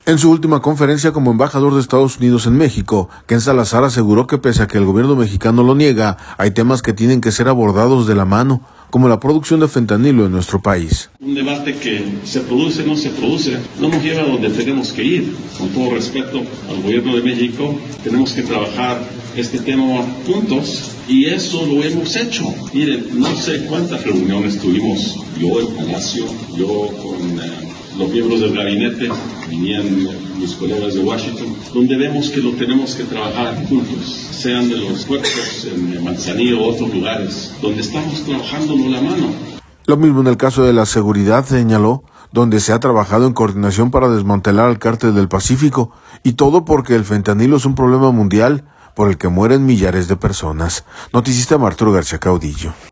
En su última conferencia como embajador de Estados Unidos en México, Ken Salazar aseguró que, pese a que el gobierno mexicano lo niega, hay temas que tienen que ser abordados de la mano, como la producción de fentanilo en nuestro país.